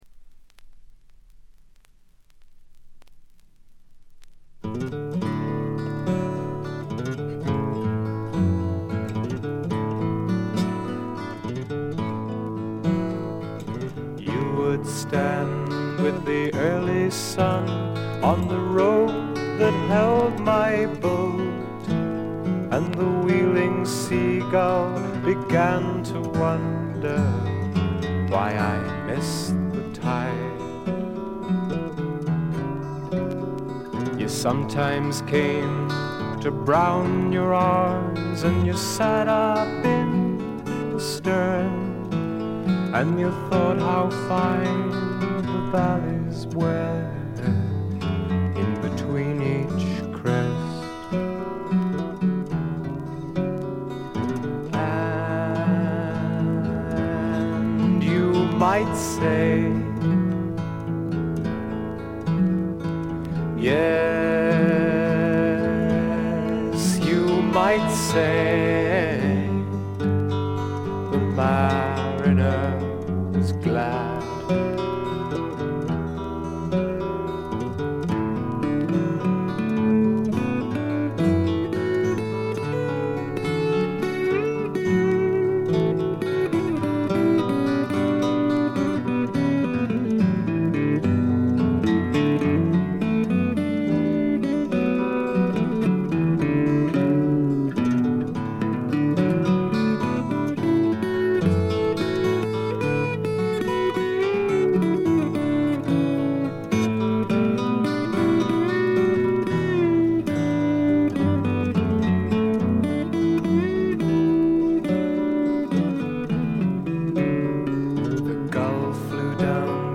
ところどころでチリプチ、プツ音。
基調は霧の英国フォークでありながらも、幻想的で、ドリーミーで、浮遊感たっぷりで、アシッドな香りも・・・。
試聴曲は現品からの取り込み音源です。